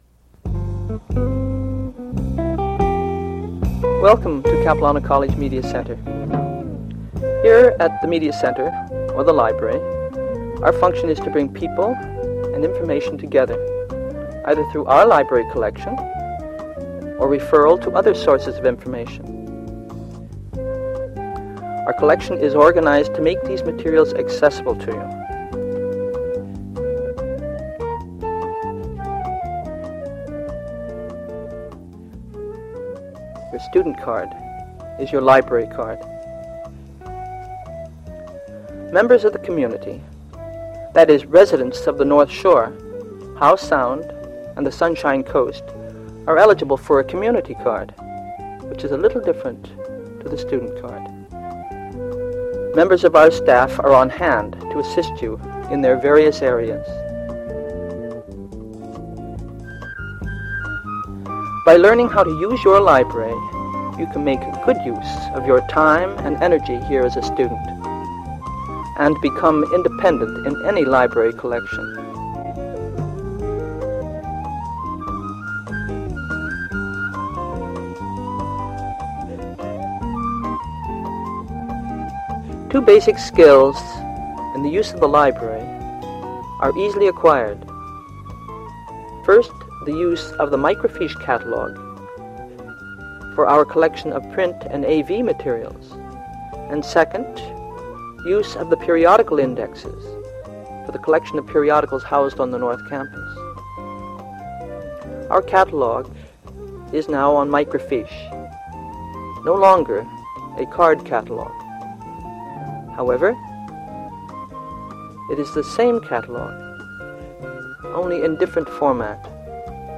Audio non-musical
Voiceover narrative with background music, describing the services and benefits of the Media Centre.
audio cassette